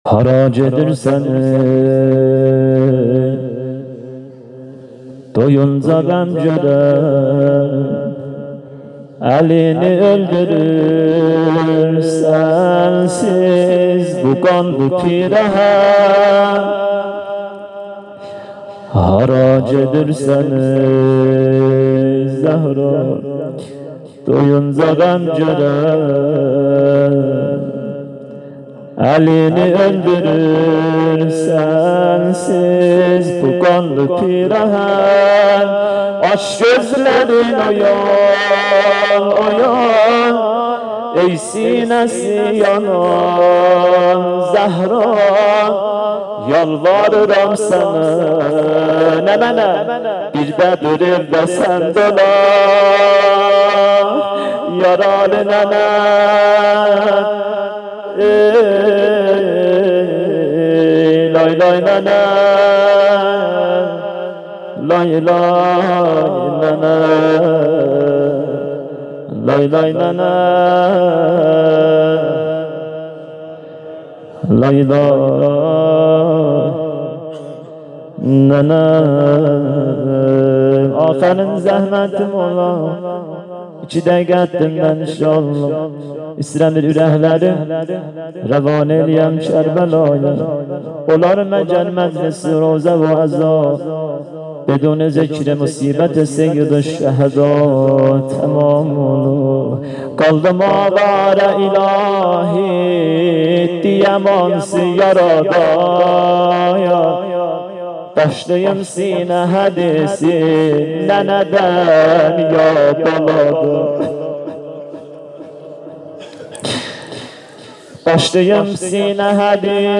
فاطمیه 97 ( اول ) - شب سوم- روضه پایانی